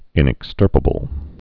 (ĭnĭk-stûrpə-bəl)